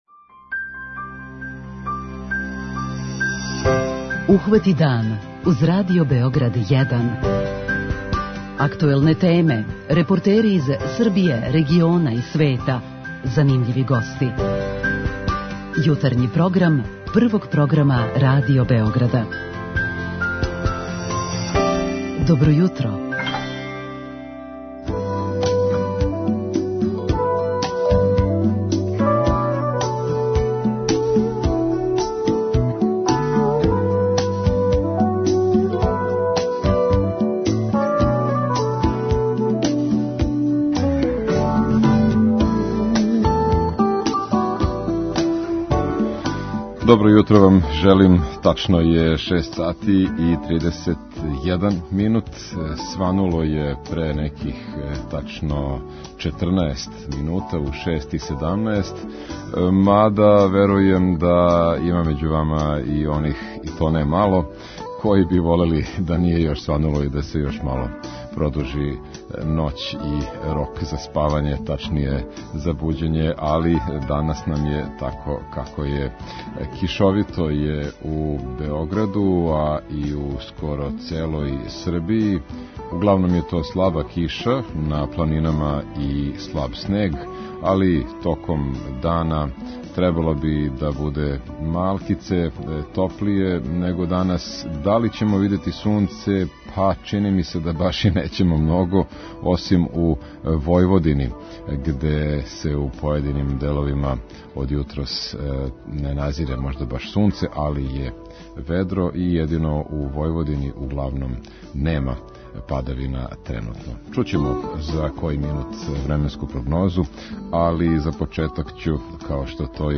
Као и сваког јутра, преносимо све најновије вести из земље, региона и света.